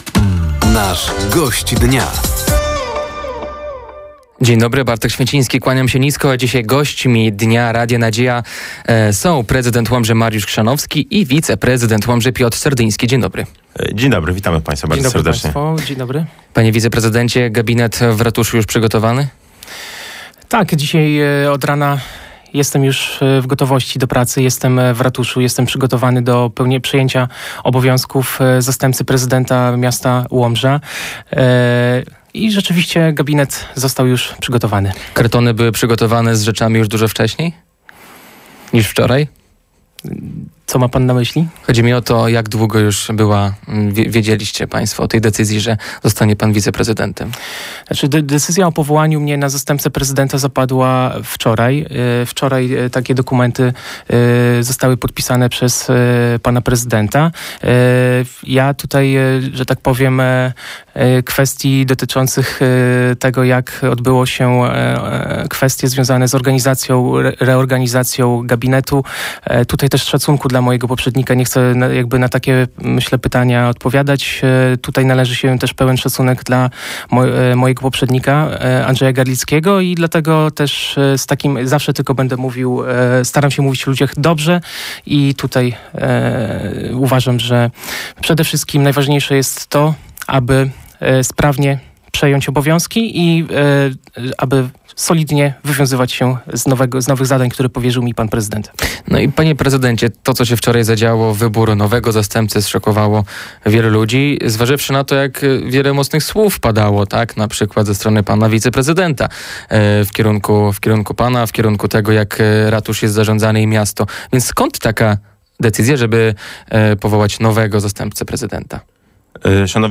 Gośćmi Dnia Radia Nadzieja byli dzisiaj prezydent Łomży, Mariusz Chrzanowski i wiceprezydent miasta, Piotr Serdyński. Tematem rozmowy była między innymi decyzja o powołaniu Piotra Serdyńskiego na stanowisko zastępcy prezydenta, który do tej pory był w opozycji do władz Łomży. Padło również pytanie o reakcję środowiska politycznego na wczorajsze wydarzenia oraz o przyszłe wybory samorządowe.